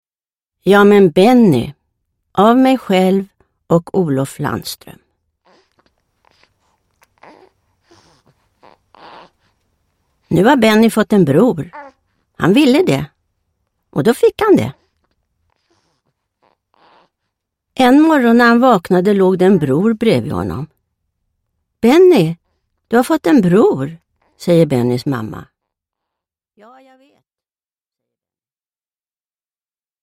Uppläsare: Barbro Lindgren